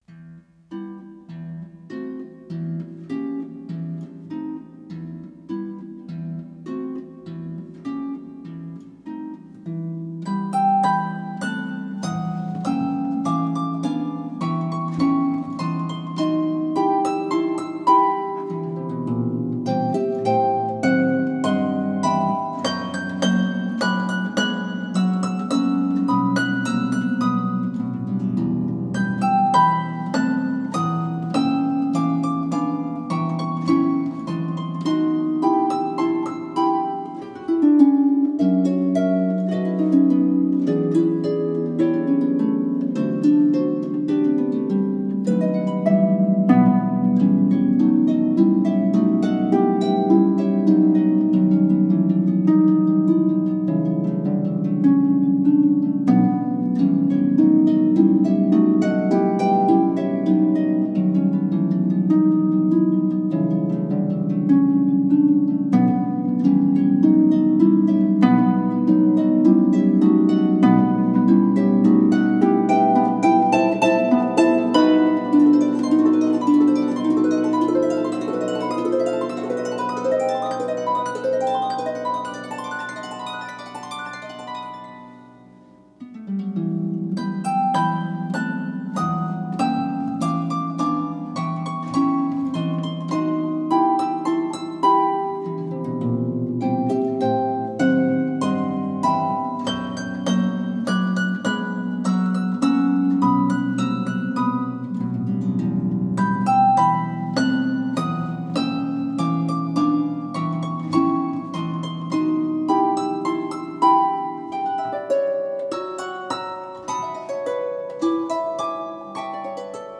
arrangement for solo pedal harp